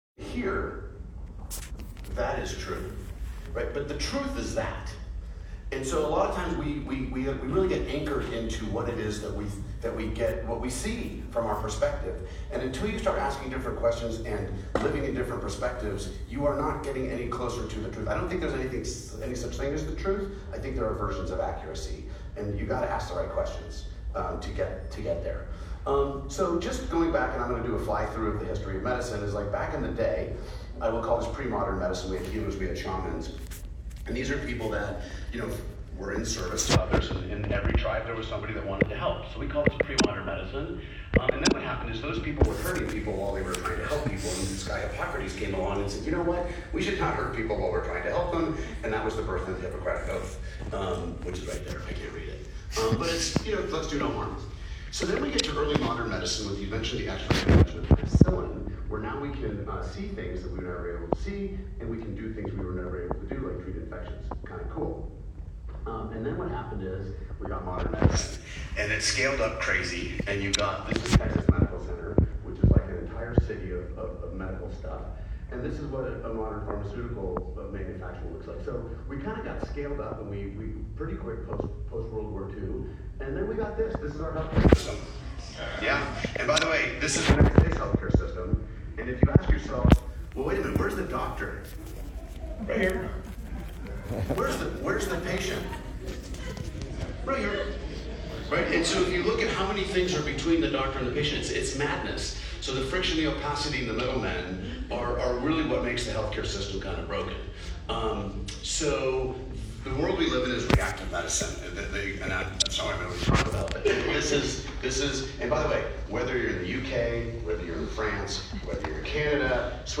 Here is the Audio File that covers most of the presentation. I’d recommend listing to it as you scroll through the slides below: (sorry for the poor quality of the audio, it’s hard to take photos and record on your phone at the same time).